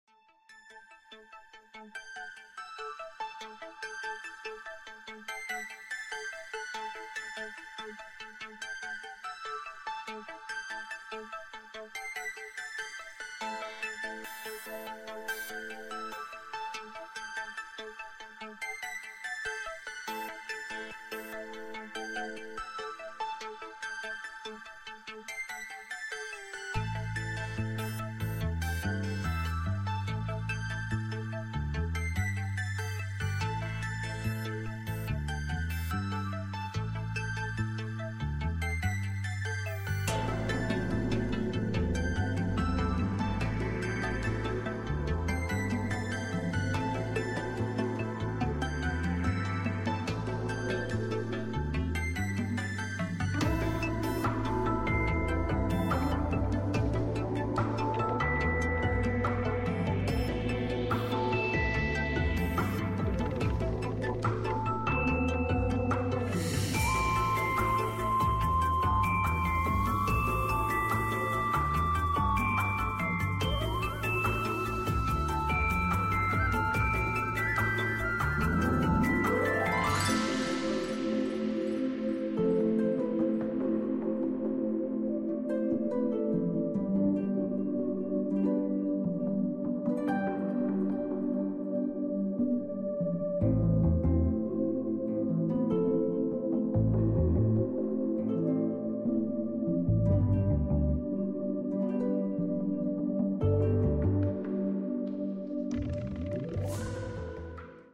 ※音量注意のため、音小さめにレンダリングしてあります